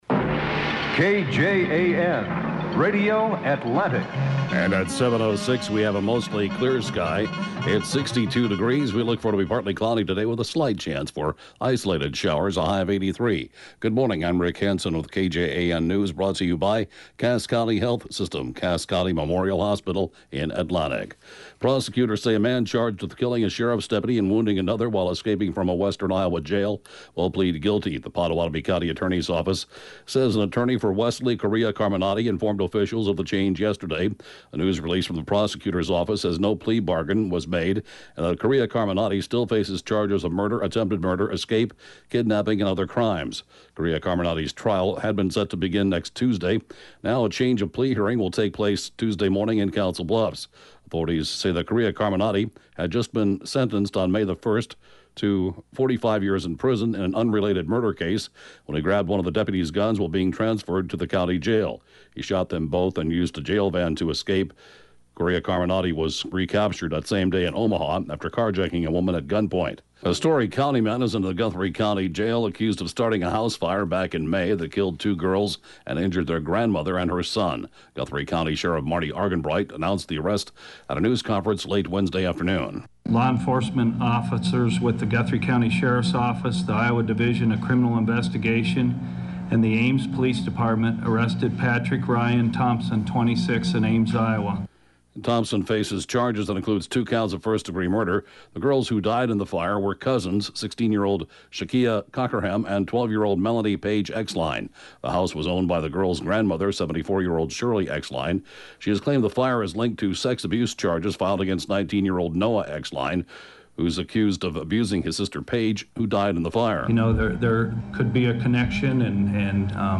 (Podcast) KJAN Morning News & funeral report, 1/6/2016